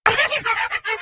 ghost_is_vulnerable.ogg